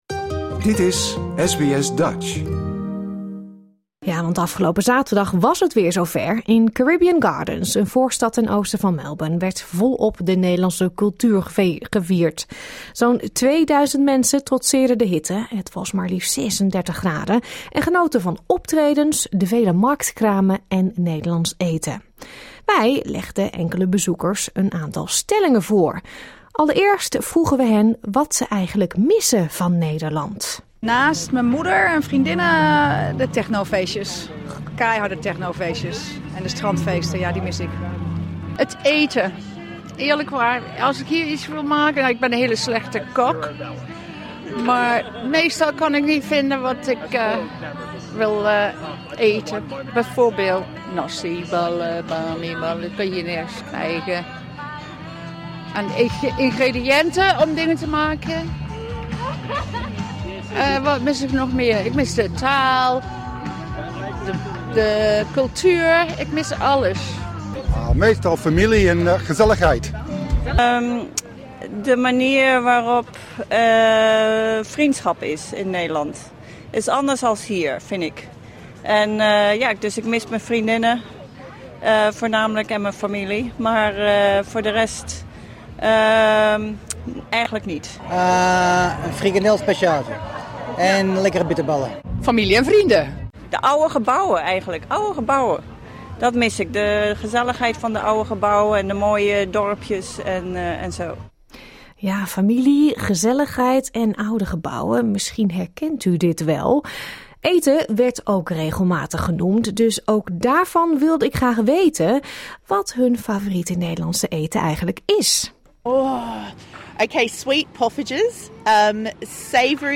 Wij waren er ook en legden bezoekers een viertal stellingen voor.